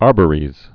(ärbə-rēz)